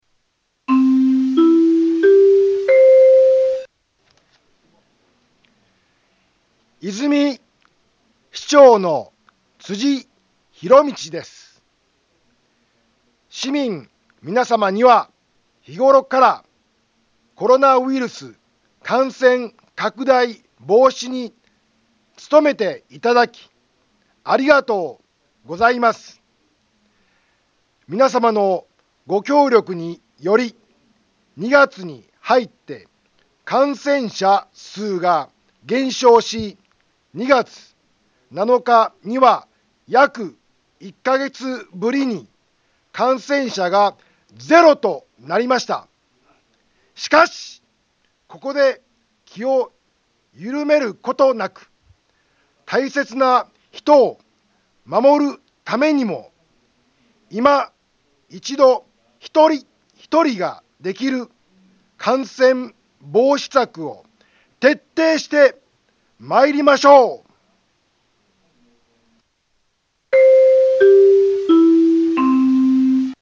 Back Home 災害情報 音声放送 再生 災害情報 カテゴリ：通常放送 住所：大阪府和泉市府中町２丁目７−５ インフォメーション：和泉市長の、辻 ひろみちです。